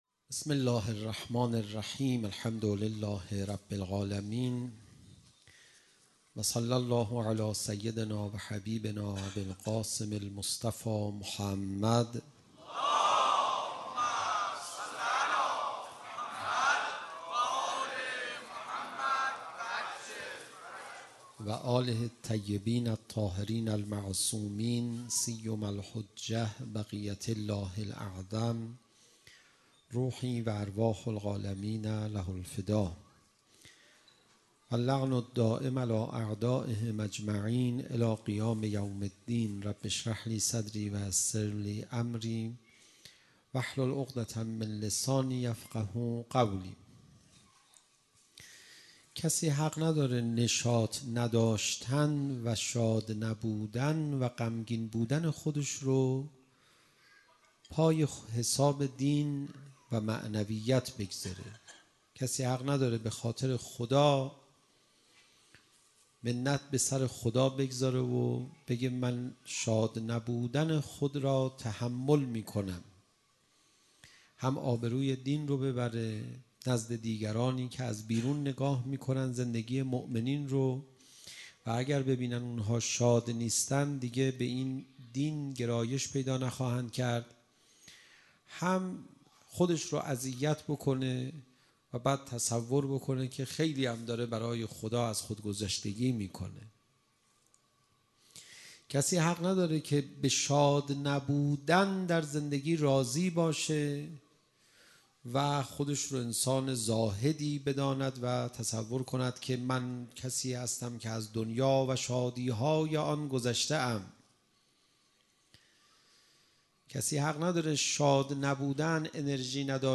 فایل های صوتی سخنرانی را از طریق لینک های زیر دریافت نمایید: